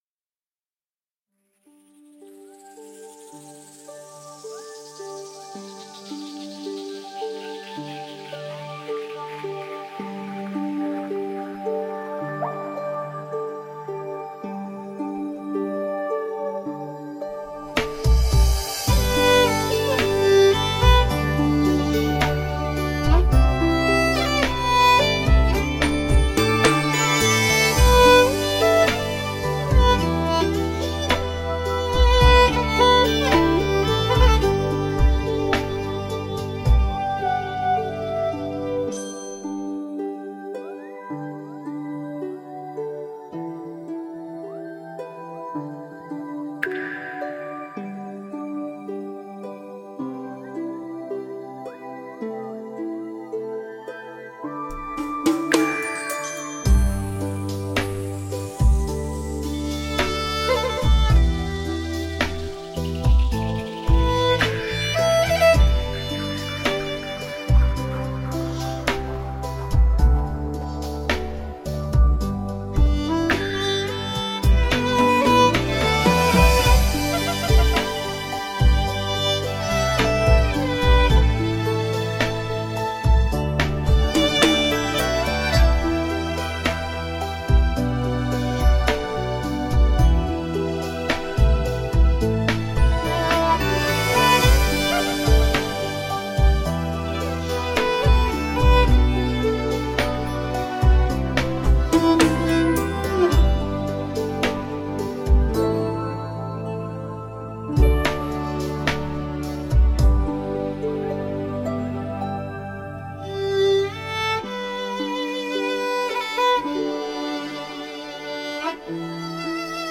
无 调式 : G 曲类